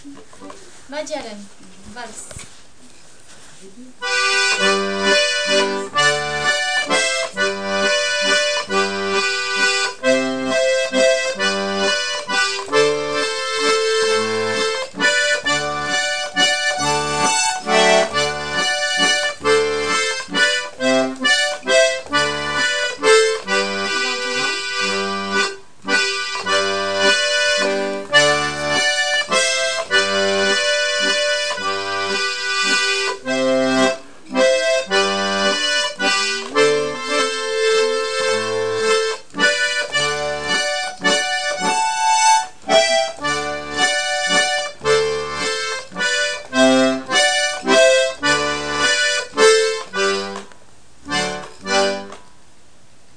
l'atelier d'accordéon diatonique
Cette valse se joue en double notes, à la tierce.